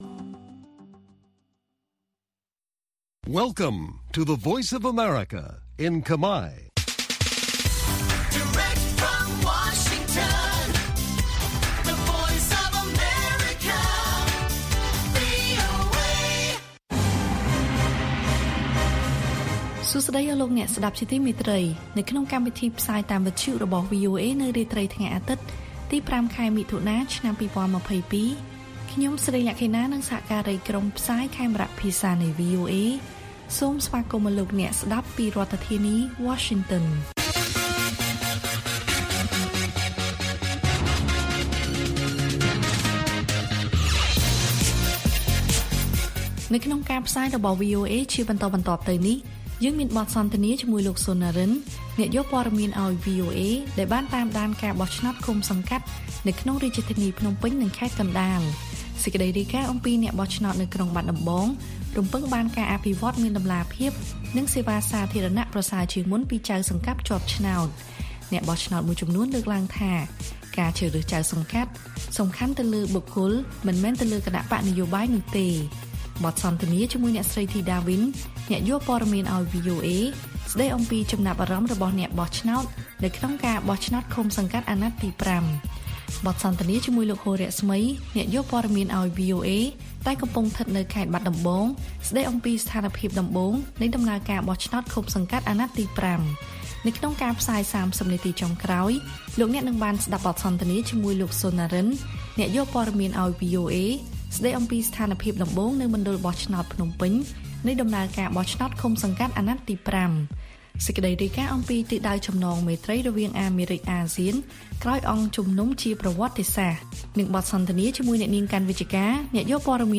ព័ត៌មានថ្ងៃនេះមានដូចជា បទសន្ទនានានាជាមួយអ្នកយកព័ត៌មានរបស់វីអូអេនៅរាជធានីភ្នំពេញនិងបាត់ដំបងអំពីស្ថានភាពក្នុងថ្ងៃបោះឆ្នោតជ្រើសរើសមេឃុំចៅសង្កាត់អាណត្តិទី៥។ អ្នកបោះឆ្នោតមួយចំនួនលើកឡើងថា ការជ្រើសរើសចៅសង្កាត់សំខាន់លើបុគ្គលមិនមែនលើគណបក្សនយោបាយនោះទេនិងព័ត៌មានផ្សេងៗទៀត៕